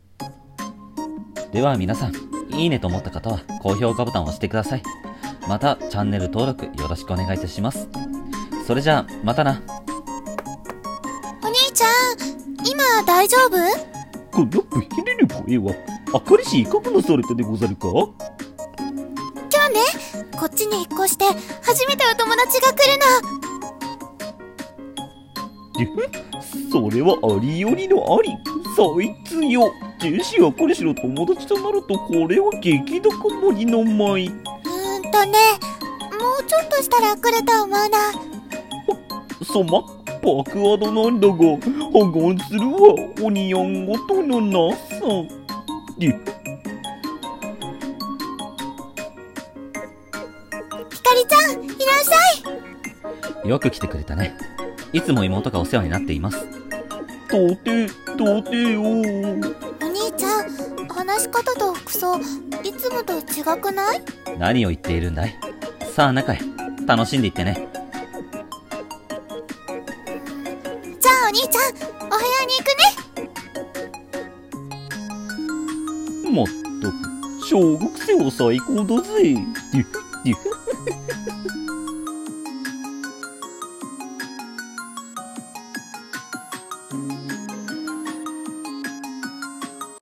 ギャグ声劇